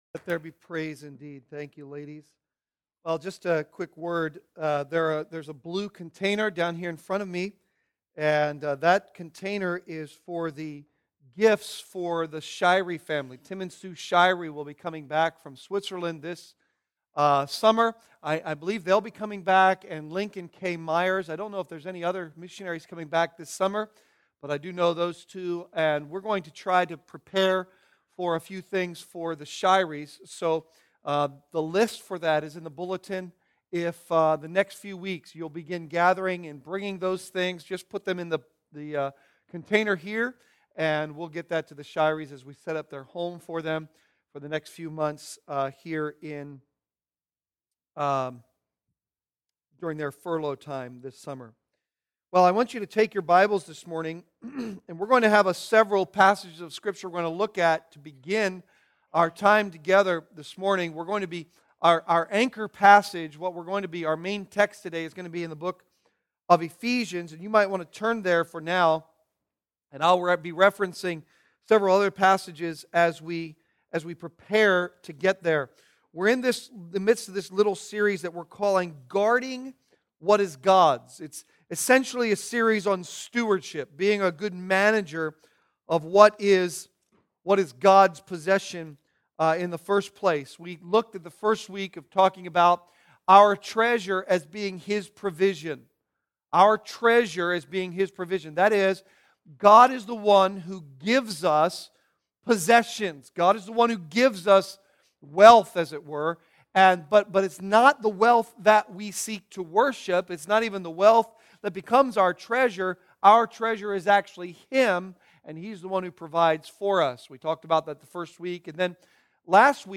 Sermons Archive - Page 67 of 95 - Calvary Bible Church - Wrightsville, PA